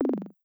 Error5.wav